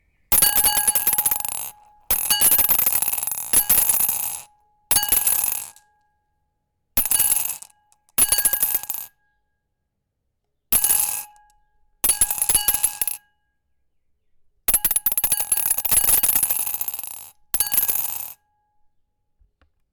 Marbels in Glass 3
Bottle clang Ding Glass Marbles Ring Ting sound effect free sound royalty free Sound Effects